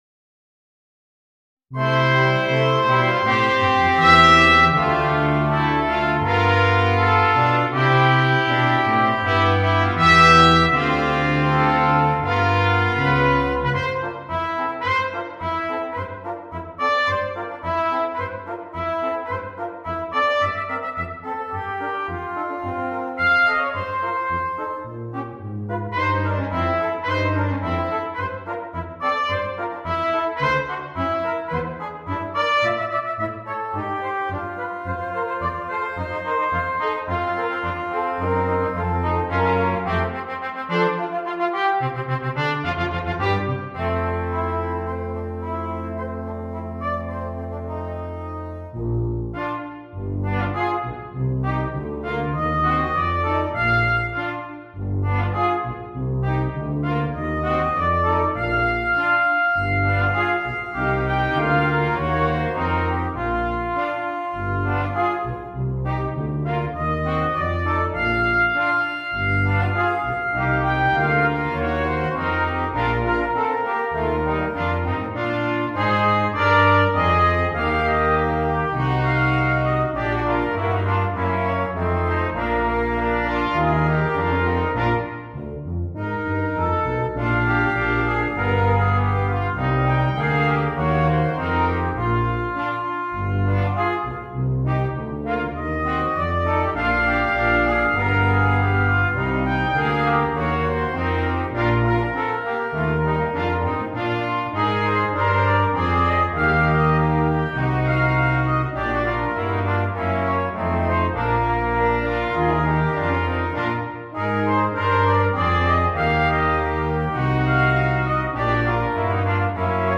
Gattung: Potpourri für 4-stimmig gemischtes Ensemble
Besetzung: Ensemble gemischt